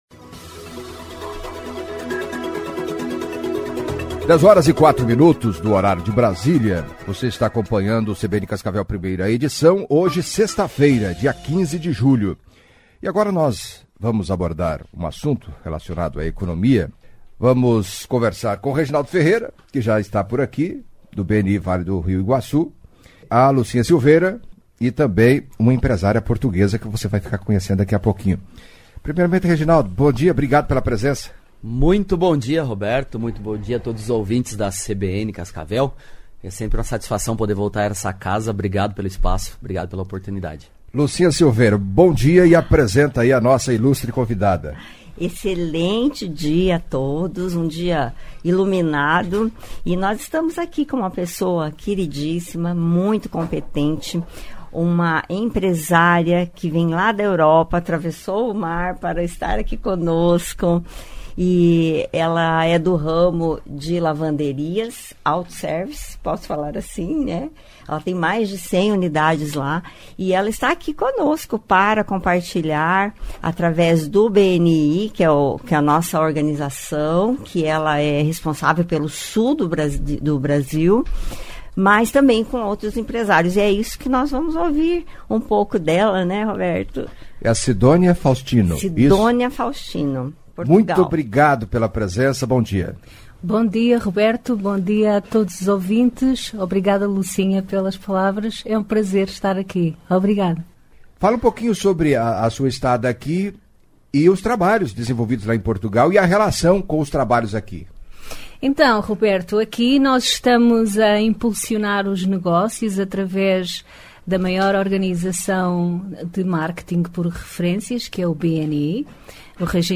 Em entrevista à CBN Cascavel